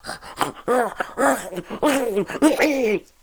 chase_14.ogg